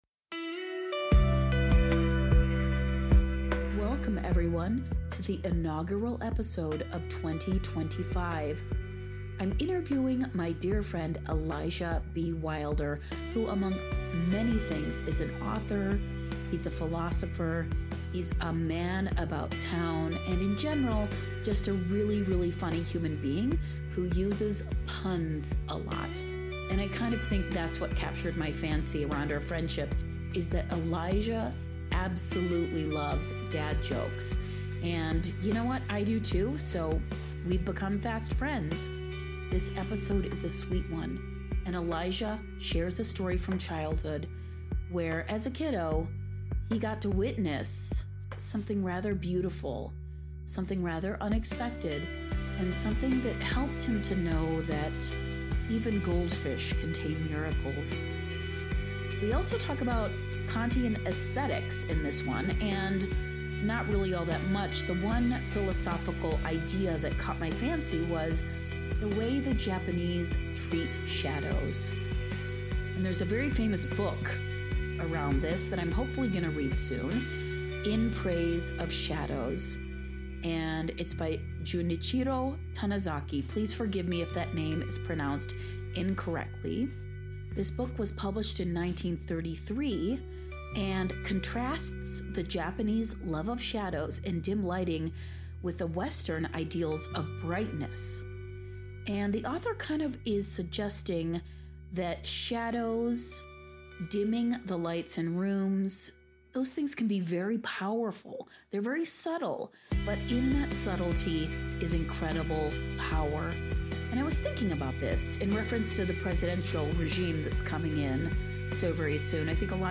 222: Interview: